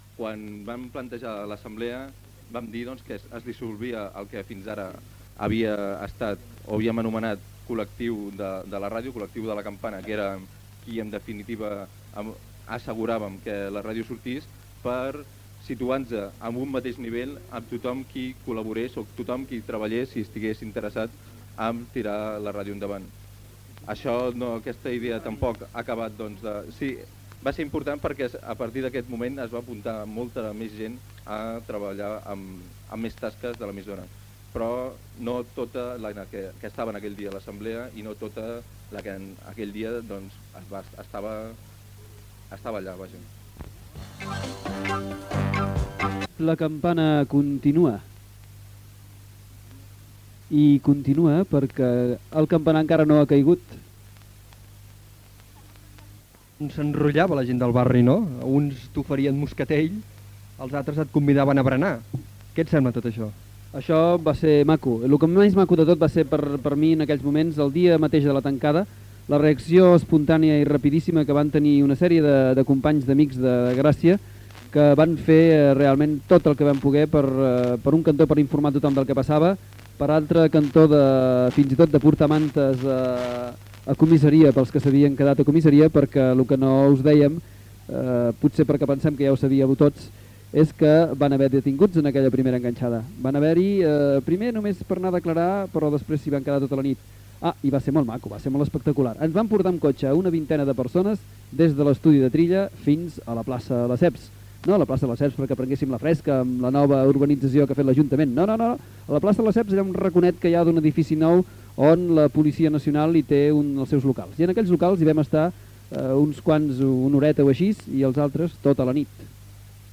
b4a54d2c4dde4e450d7dc68cf75bc58642a70155.mp3 Títol La Campana Emissora La Campana Titularitat Tercer sector Tercer sector Lliure Descripció Història de l'emissora explicada pel col·lectiu que tirava endavant l'emissora l'any 1981. Gènere radiofònic Informatiu